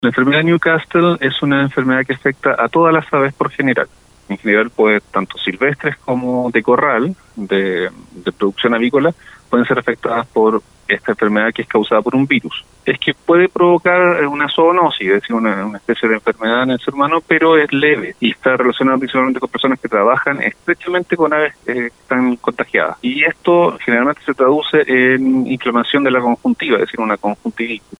Así lo comentó en conversación con Radio Bío Bío